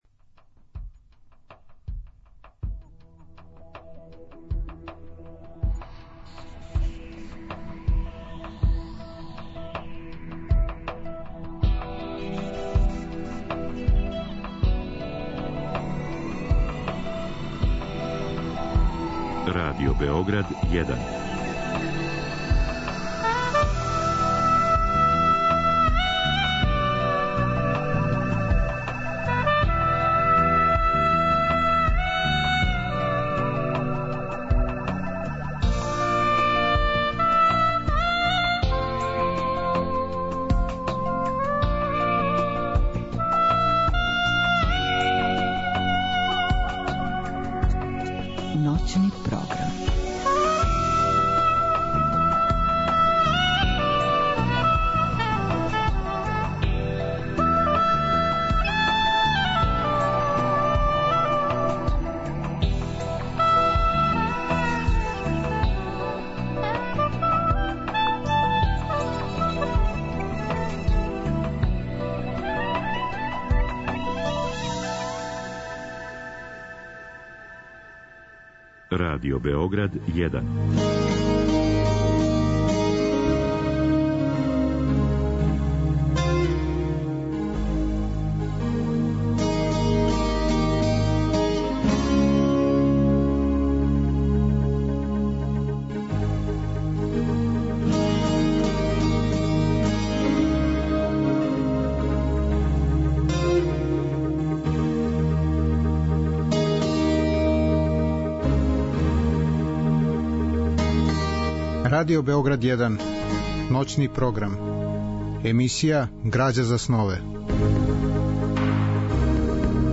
Разговор и добра музика требало би да кроз ову емисију и сами постану грађа за снове.